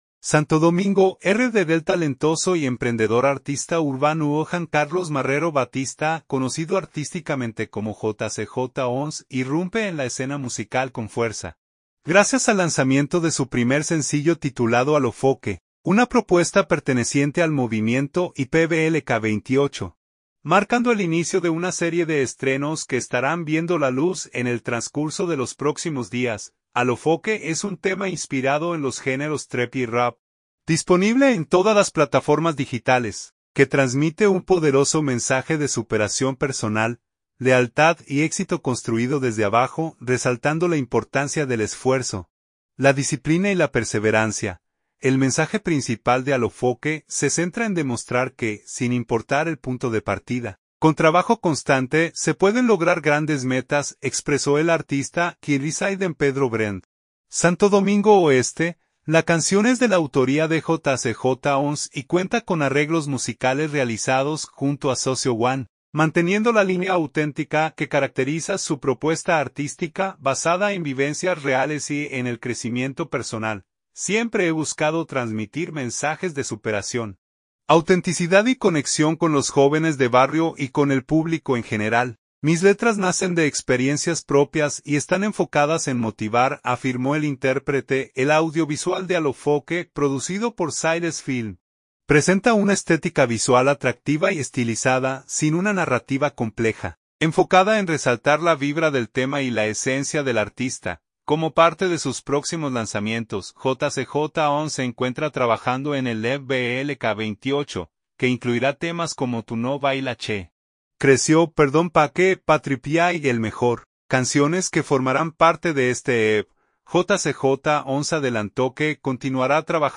artista urbano
Trap y Rap